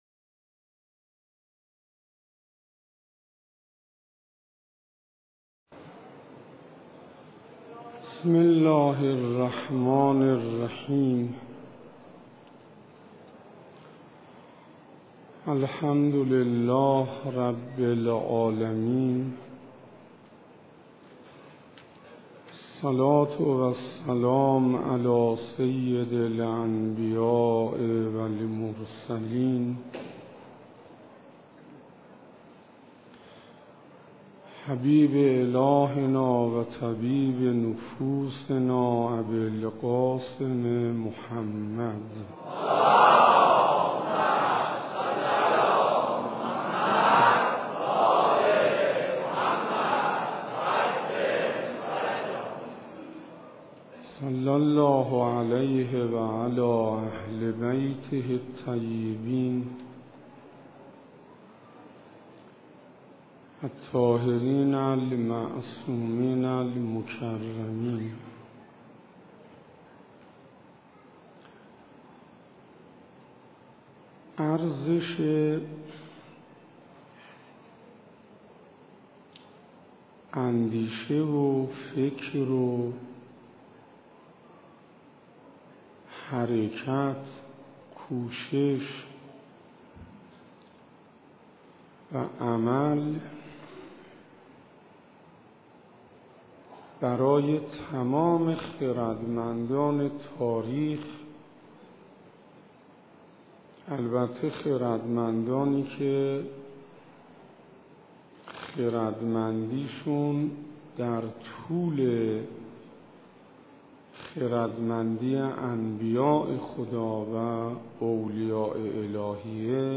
سخنرانی حجت الاسلام انصاریان